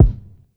Old School Kick.wav